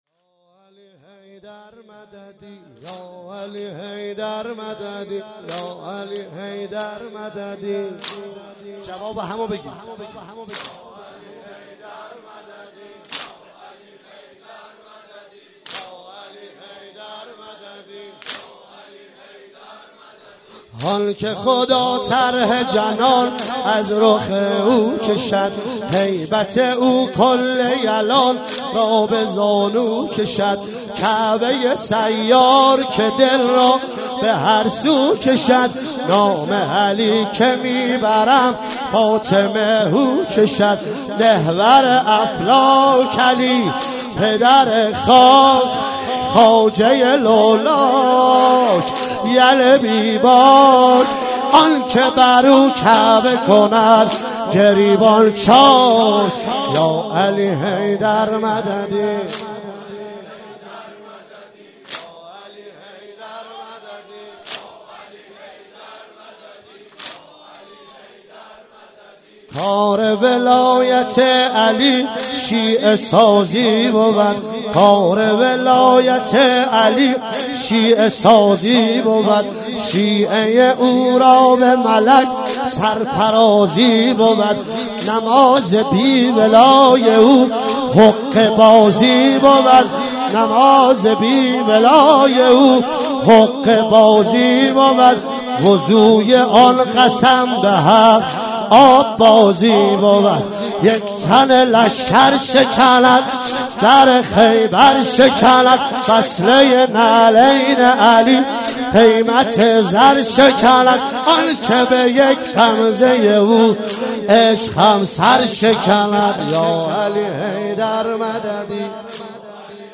واحد تند